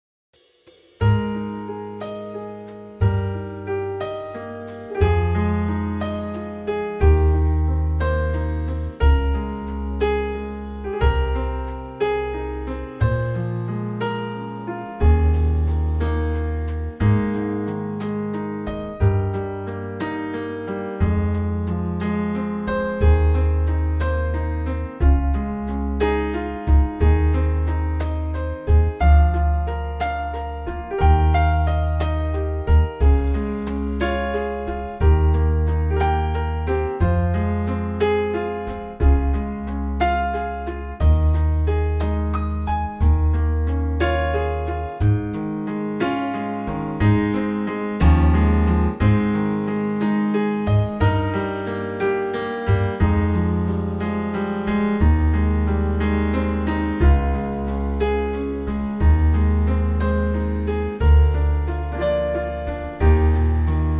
Unique Backing Tracks
key - Ab - vocal range - Eb to G (optional Ab top note)
Gorgeous Trio arrangement